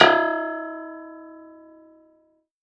53x-pno01-E2.wav